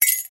Buy Item.wav